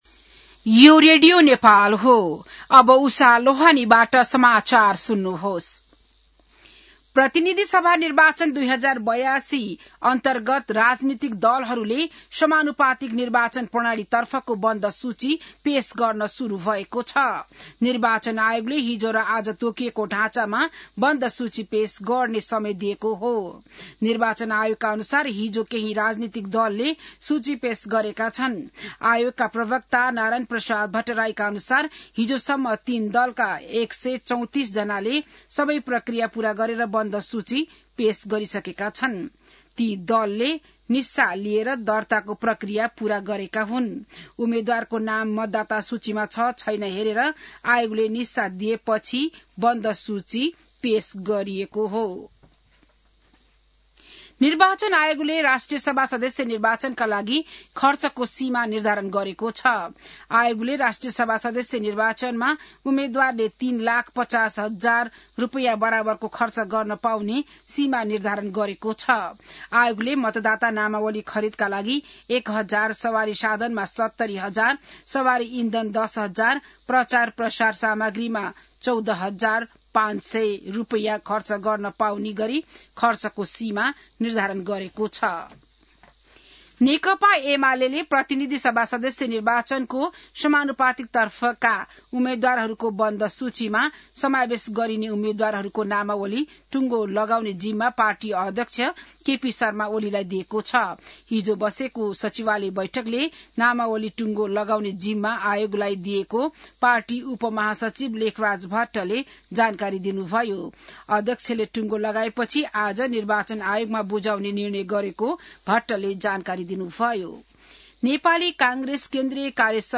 बिहान १० बजेको नेपाली समाचार : १४ पुष , २०८२